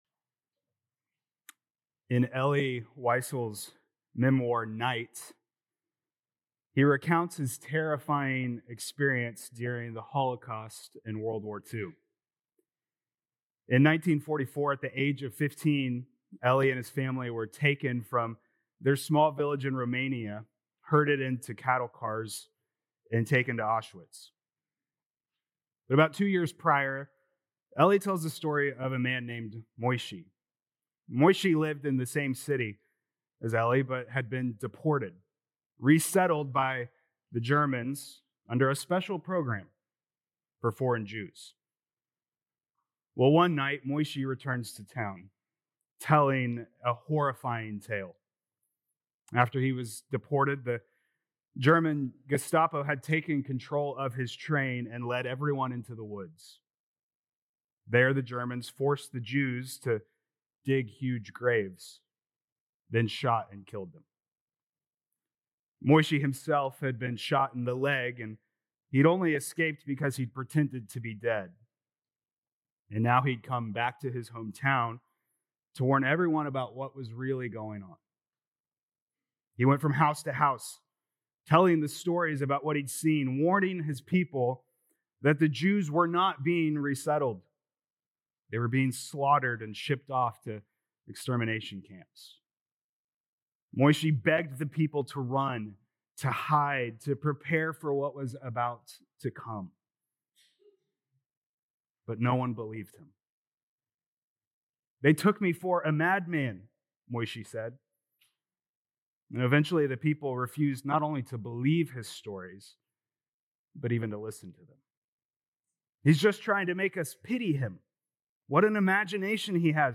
Nov 2nd Sermon